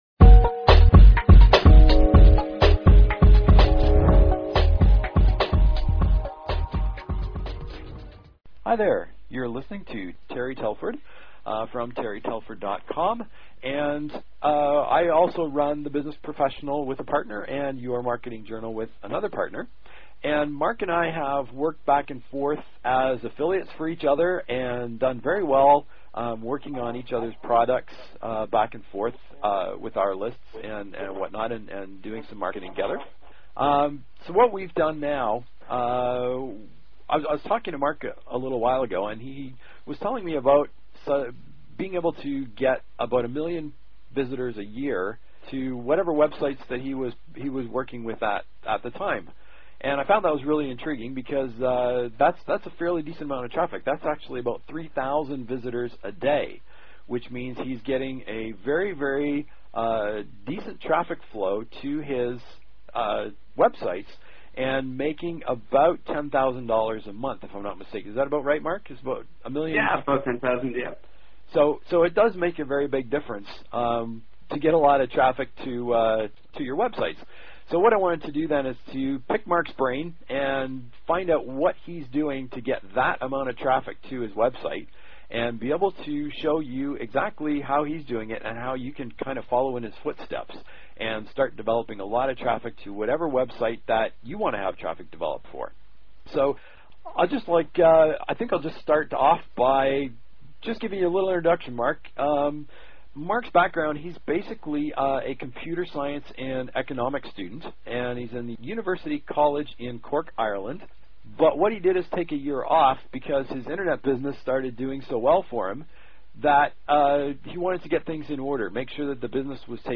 Web Marketing Explained! The Tell All Interview!